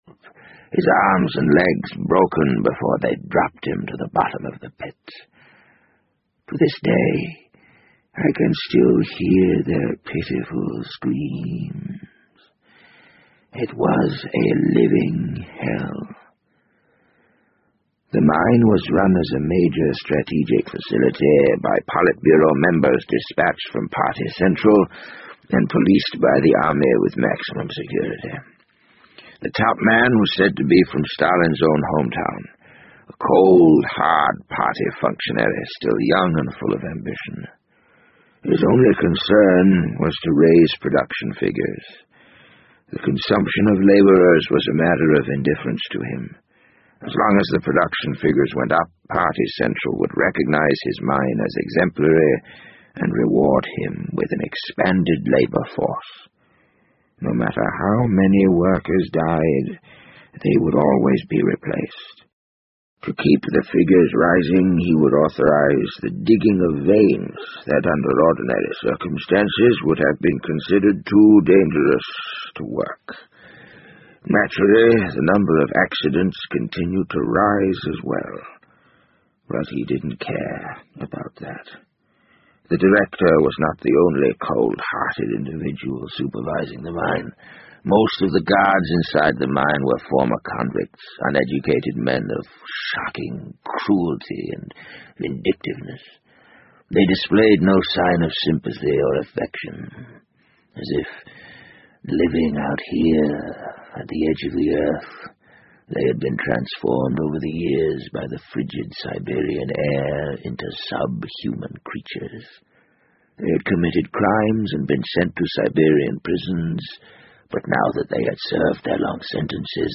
BBC英文广播剧在线听 The Wind Up Bird 014 - 3 听力文件下载—在线英语听力室